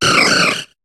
Cri de Jirachi dans Pokémon HOME.